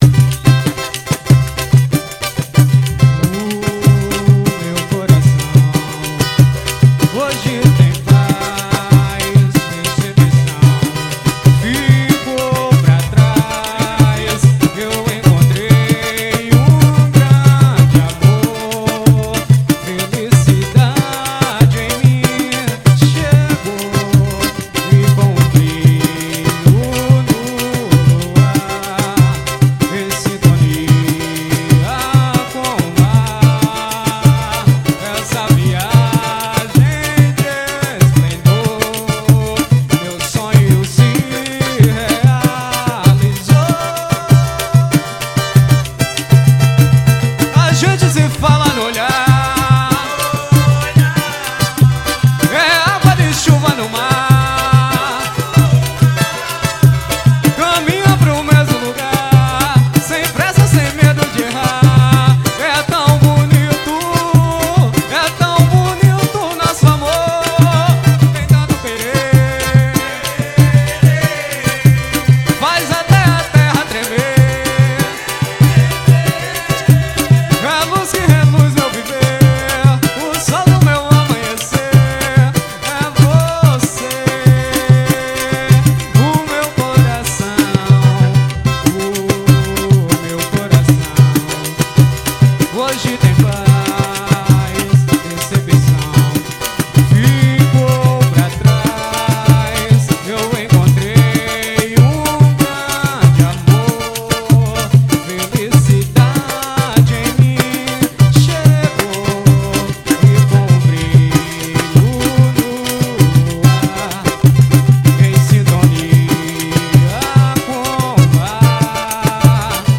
Samba.